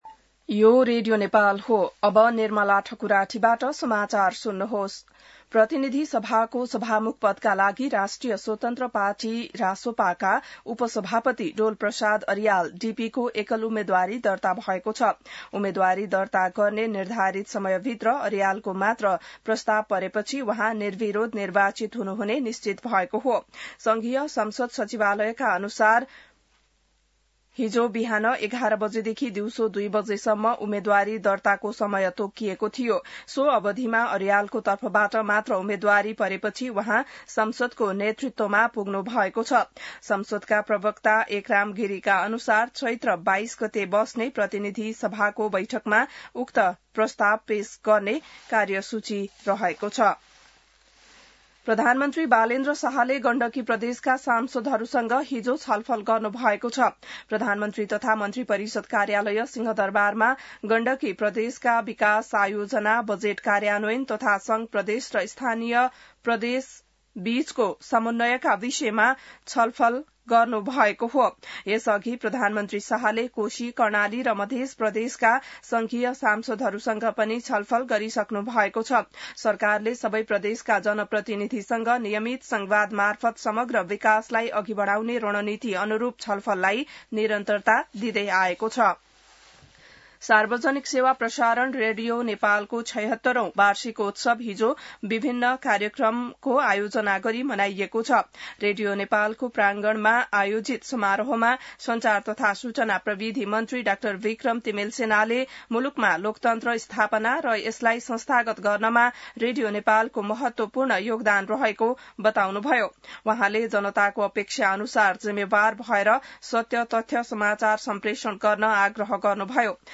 An online outlet of Nepal's national radio broadcaster
बिहान ६ बजेको नेपाली समाचार : २१ चैत , २०८२